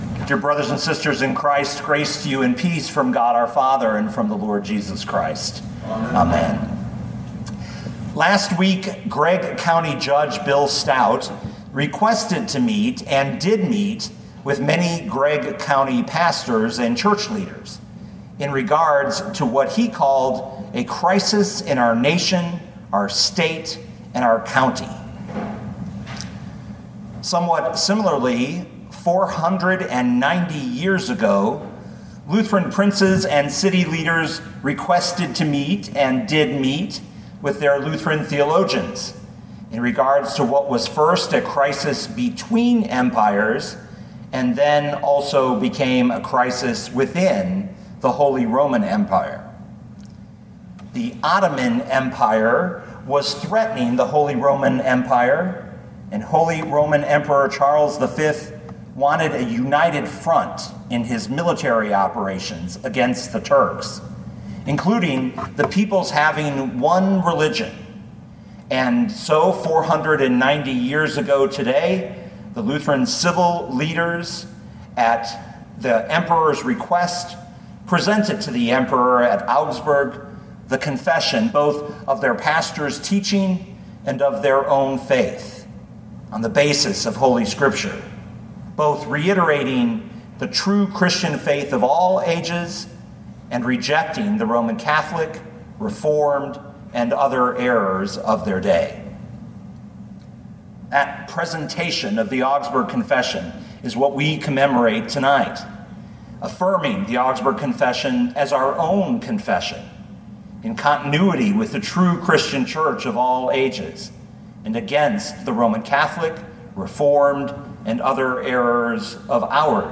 2020 John 15:1-11 Listen to the sermon with the player below, or, download the audio.